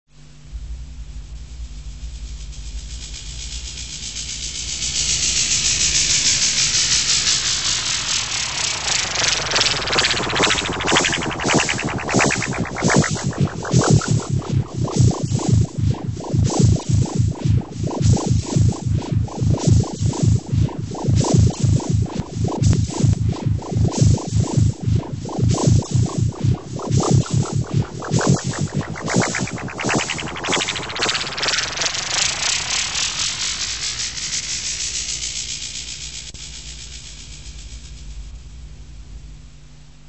На этой странице собраны звуки, которые ассоциируются с инопланетянами и пришельцами: странные сигналы, электронные помехи, \
Приземление неопознанного объекта на Землю